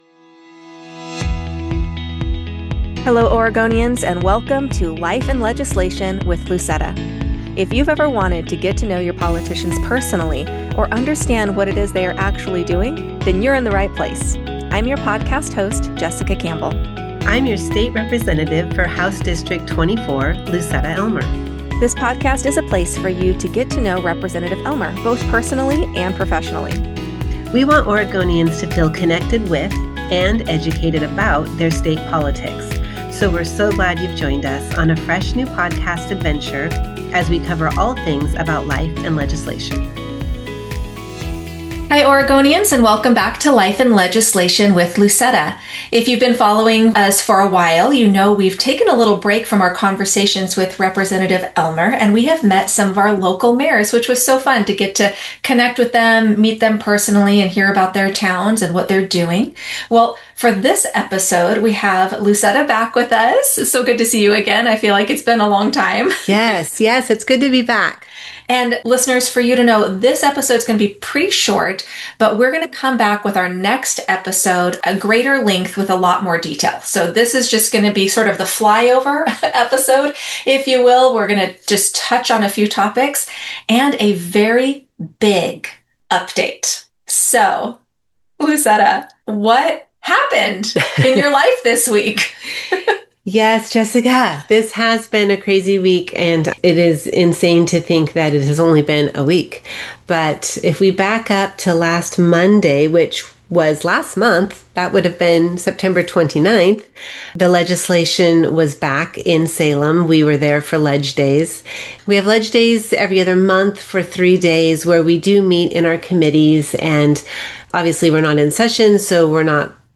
In this episode, we briefly catch up with Representative Elmer to hear about some big developments over the past week.